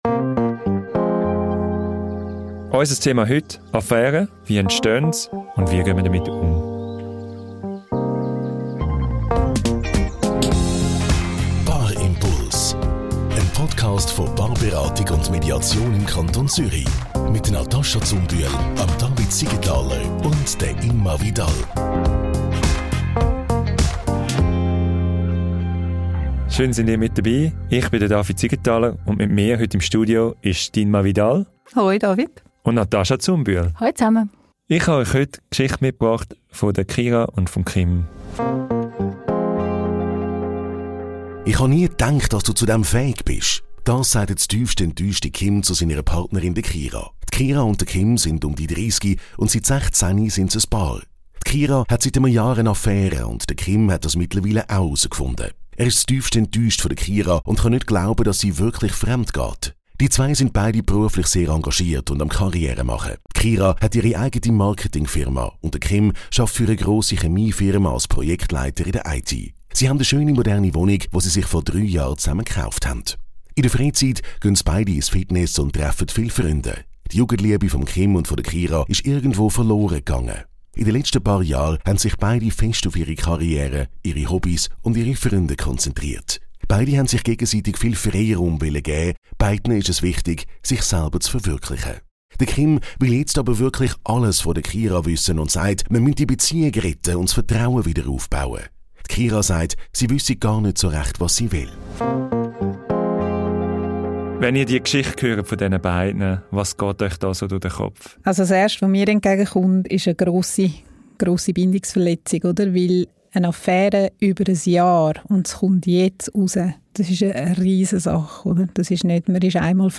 Gemeinsam beleuchten die Paarberater*innen mögliche Ursachen und Dynamiken – von emotionaler Distanz und unerfüllten Bedürfnissen bis hin zu Krisen und persönlichen Herausforderungen.